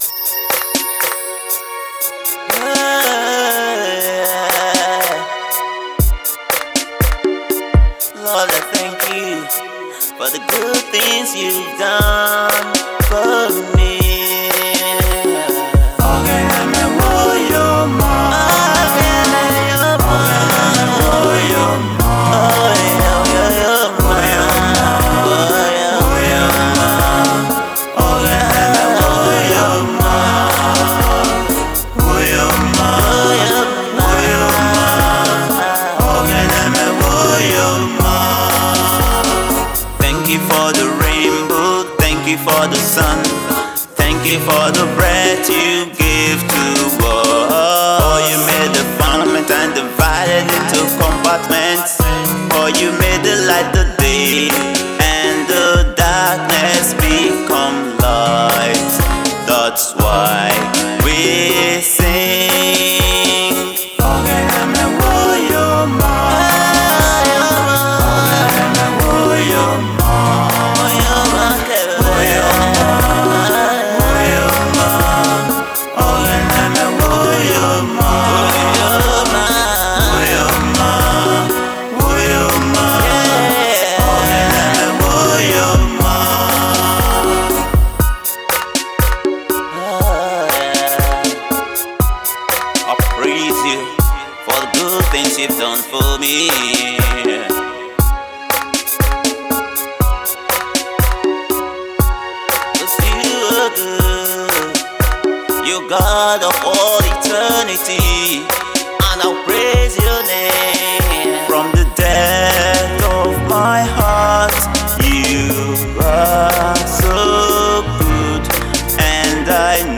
2020-01-02 New Music 8 Comments